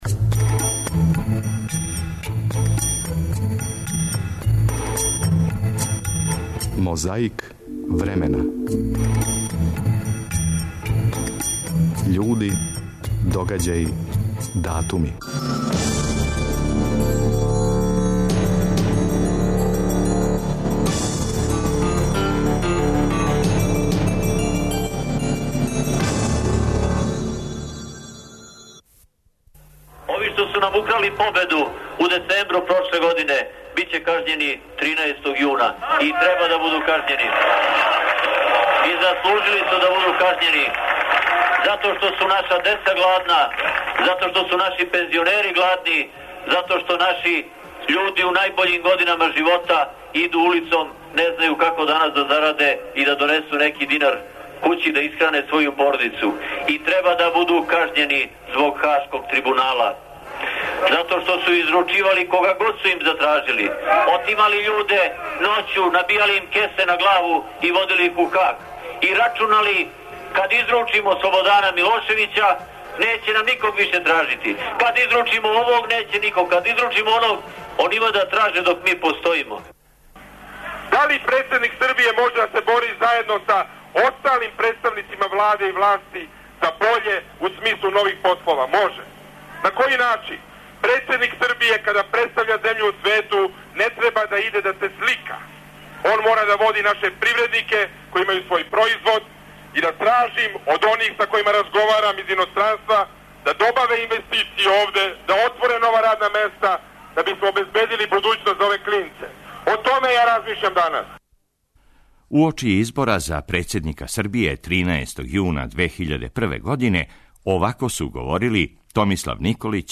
Имамо и звучну коцкицу догађаја од 13. јуна 1993. године на Равној Гори.